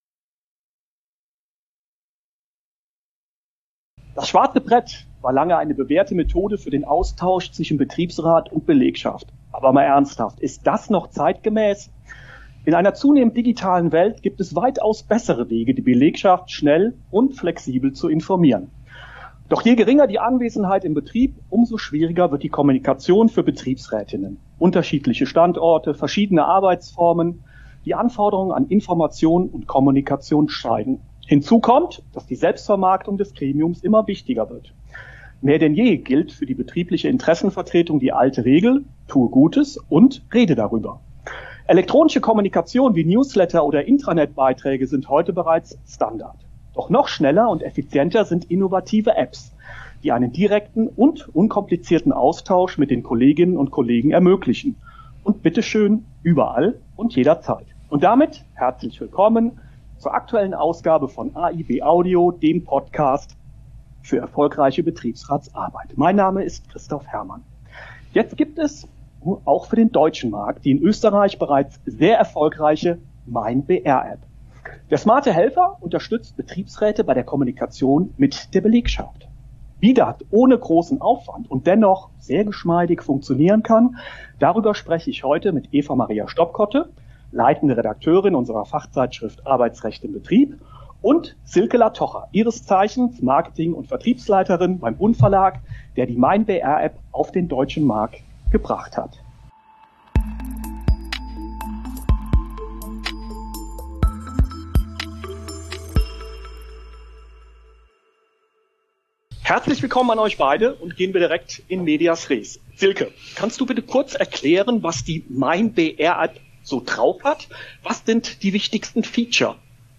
In AiB Audio zeigen Betriebsräte, wie sie sich erfolgreich für ihre Beschäftigten eingesetzt haben und geben anschaulich Tipps zur Umsetzung auch in anderen Betrieben. Namhafte Experten beleuchten aktuelle Trends in Rechtsprechung, Politik und betrieblichen Praxis.